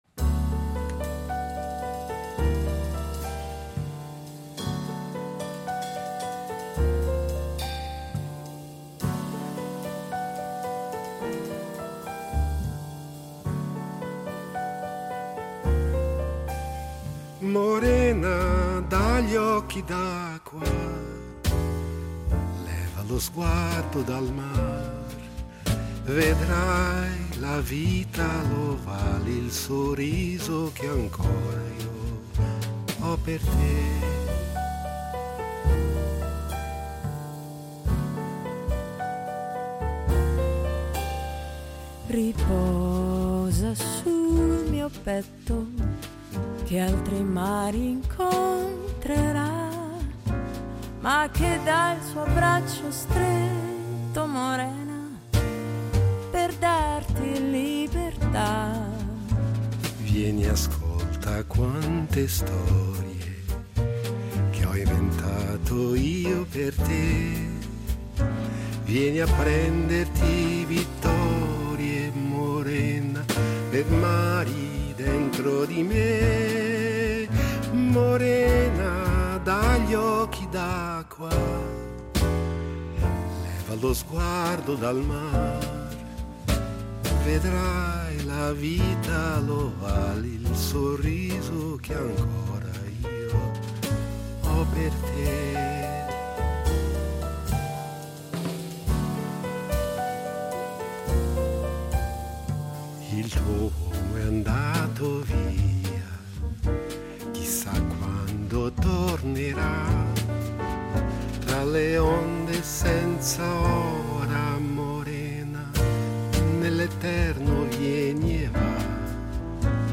La cantante italiana si racconta